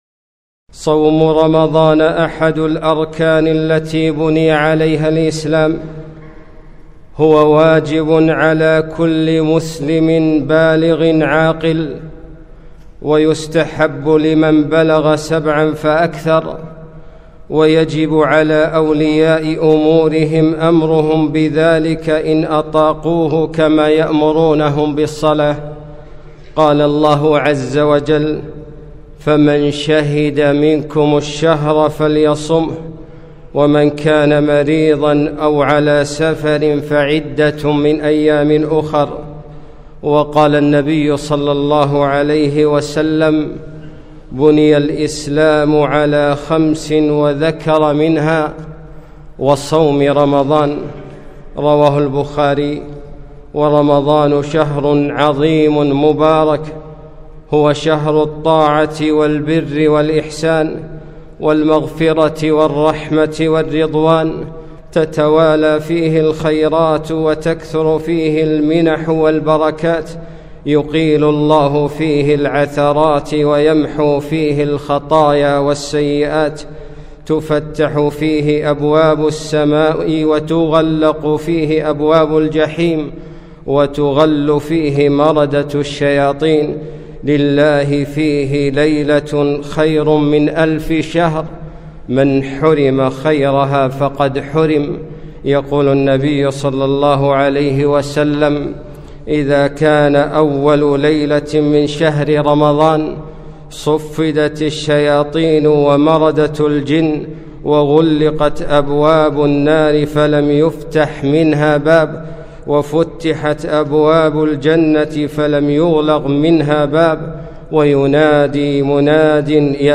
خطبة - شهر رمضان